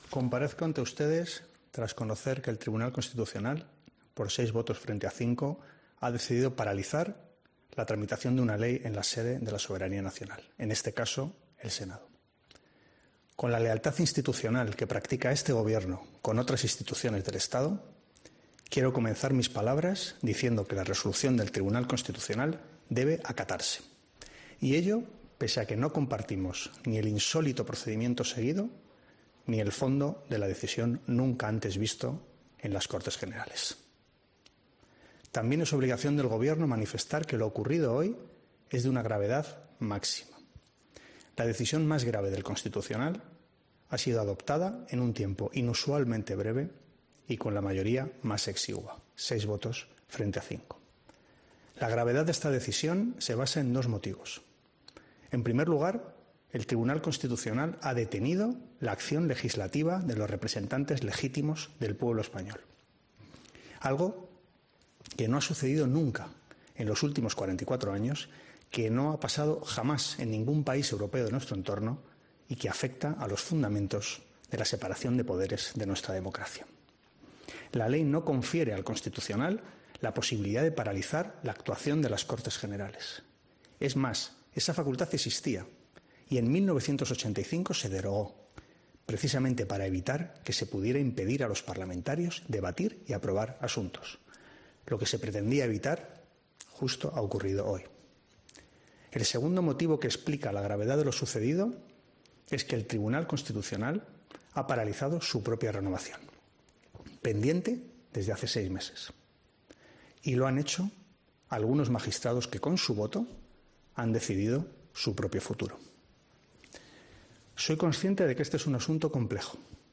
Las palabras del ministro de la Presidencia, Félix Bolaños, tras la decisión del TC
Así lo ha expresado en nombre del Ejecutivo el ministro de la Presidencia, Félix Bolaños, en una declaración institucional pasada la medianoche de este lunes, en el Palacio de la Moncloa, tras conocer esa decisión del Tribunal Constitucional.